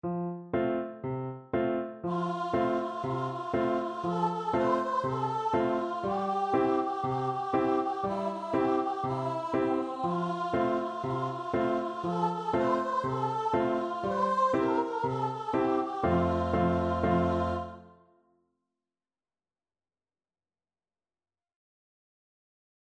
Voice
F major (Sounding Pitch) (View more F major Music for Voice )
4/4 (View more 4/4 Music)
Allegro =c.120 (View more music marked Allegro)
C5-C6
Traditional (View more Traditional Voice Music)
mahunga_pakahiwi_VO.mp3